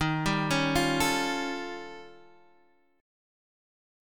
Eb7#11 chord